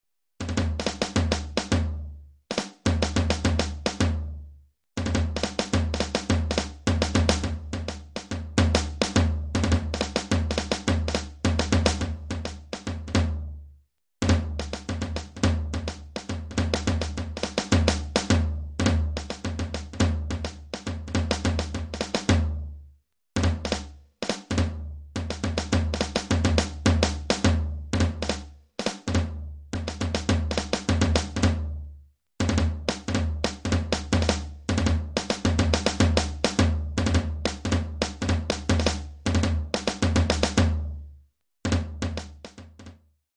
Oeuvre pour tambour seul.